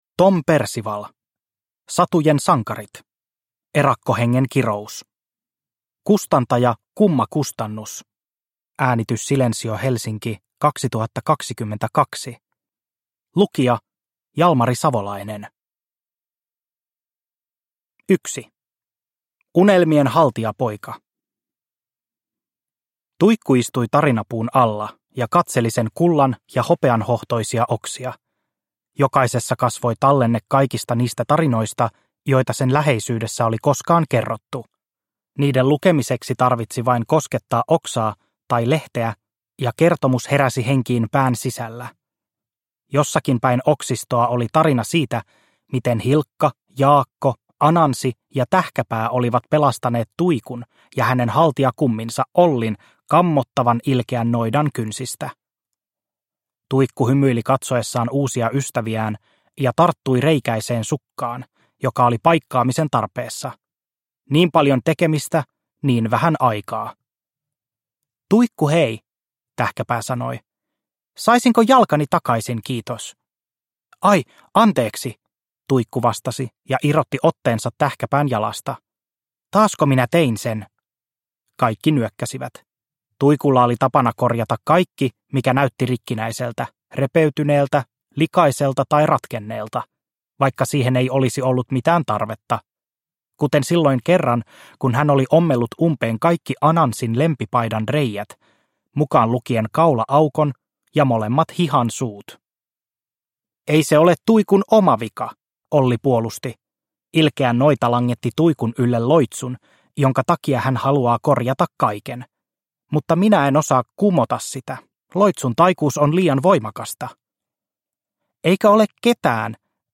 Erakkohengen kirous – Ljudbok – Laddas ner